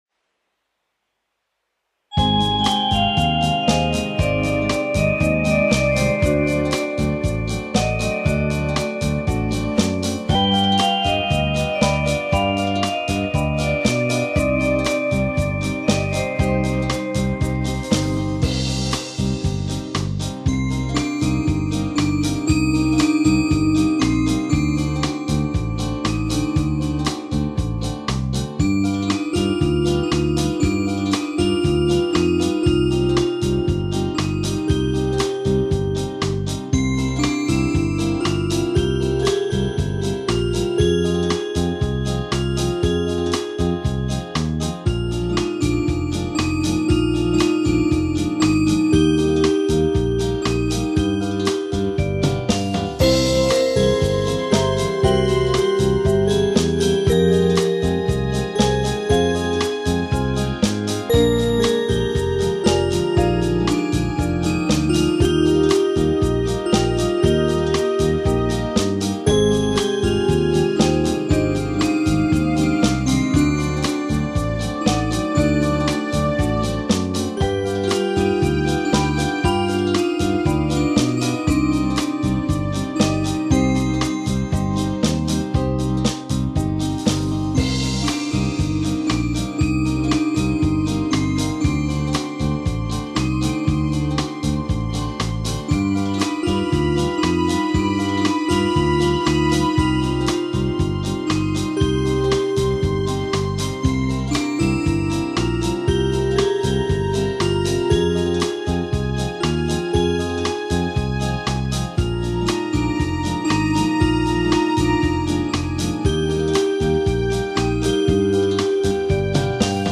Чарівно-заколисуюче! 16 Приємно слухати такі чуттєві мелодії на ніч! 31 flo08 flo34 flo08